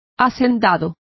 Complete with pronunciation of the translation of planters.